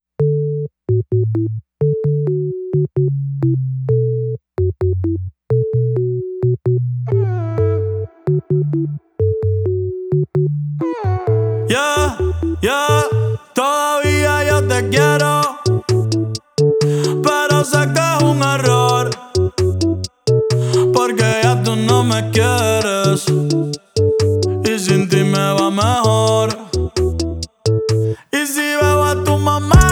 Genre: Urbano latino